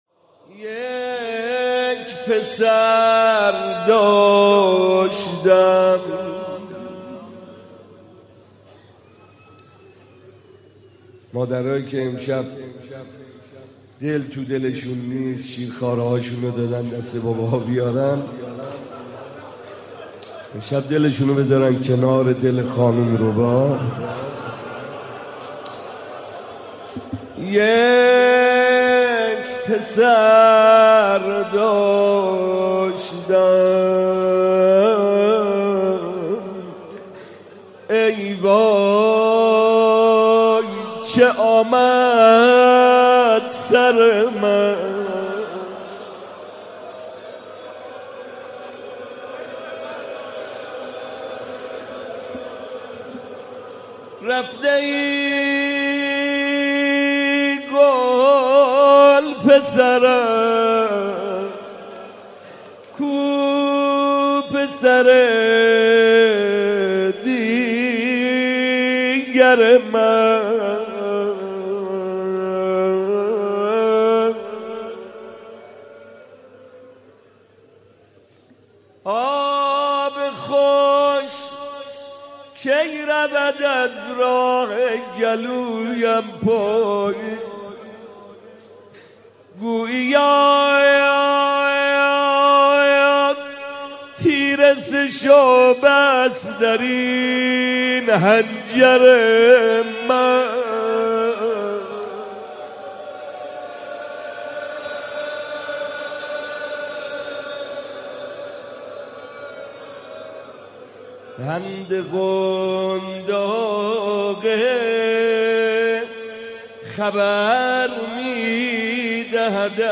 مداحی جدید حاج محمدرضا طاهری شب هفتم محرم97 هیئت مکتب الزهرا
روضه
نوحه محرم